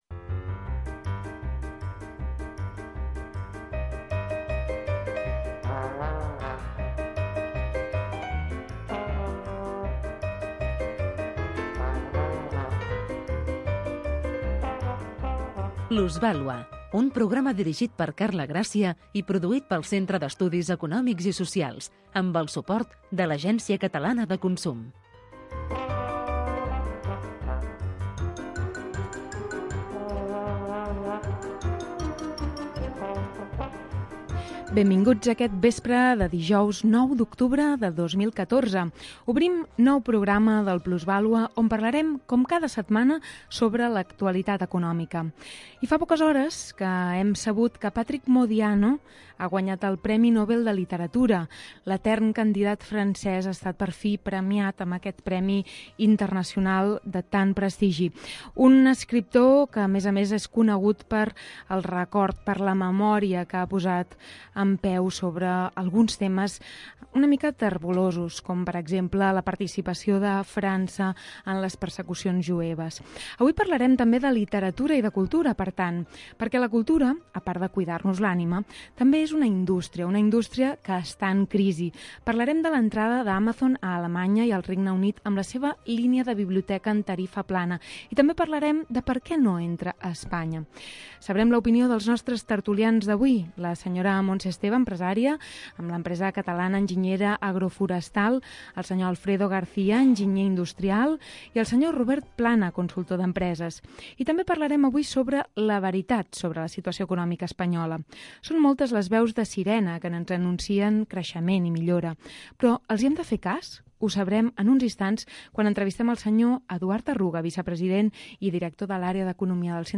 Un programa amb entrevistes i tertúlia sobre economia amb clau de valors humans, produït pel CEES